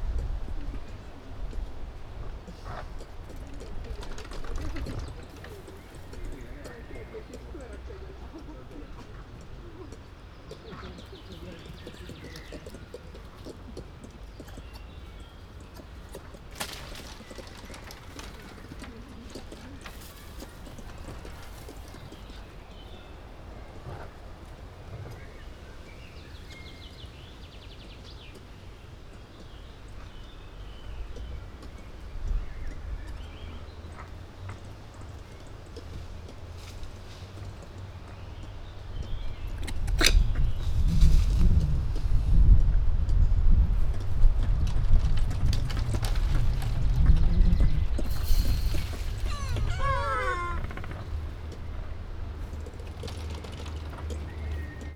Directory Listing of /_MP3/allathangok/veszpremizoo2013_premium/gyurusfarkumaki/